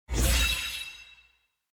UI_Levelup.mp3